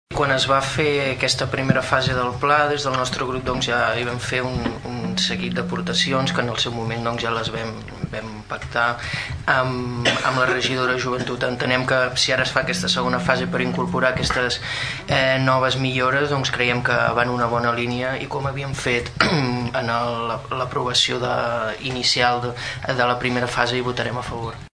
El regidor del PP, Xavier Martín, va exposar el vot favorable del seu grup, tal i com ja van fer amb la primera fase del pla.